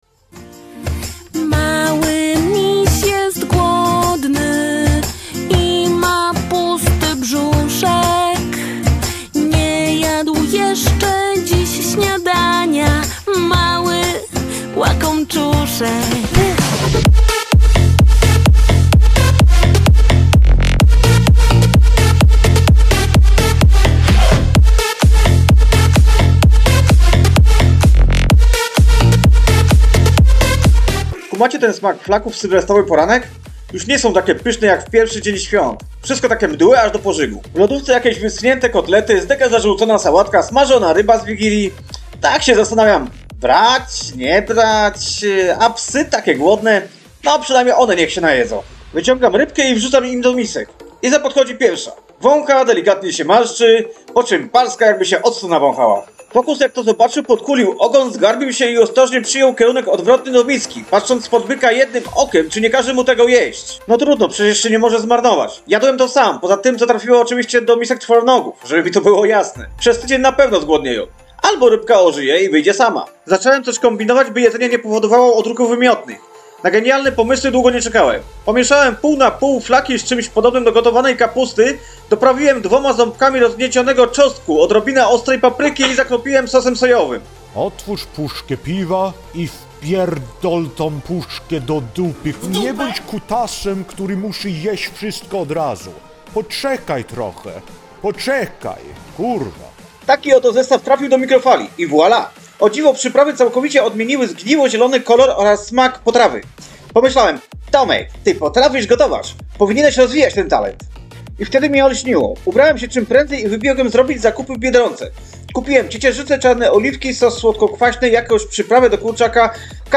Czytał sam